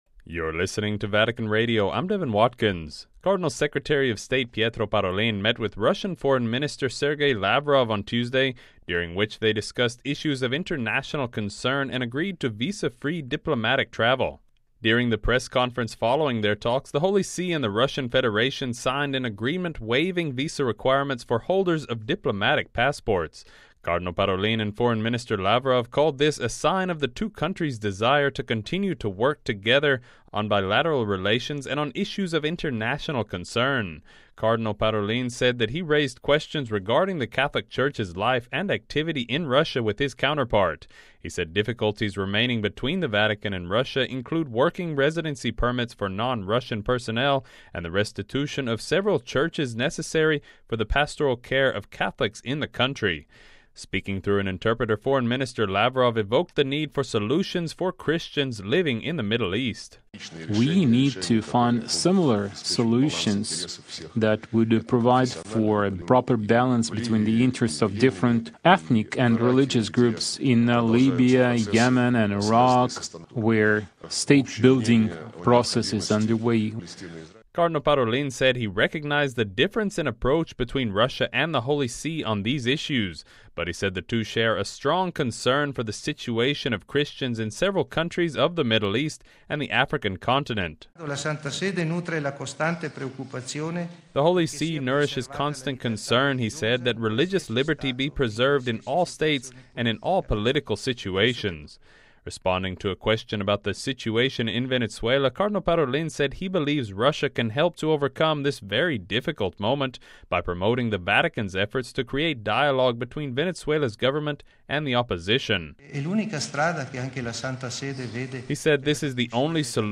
During the press conference following their talks, the Holy See and the Russian Federation signed an Agreement waiving visa requirements for holders of diplomatic passports.